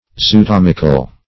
Zootomical \Zo`o*tom"ic*al\, a.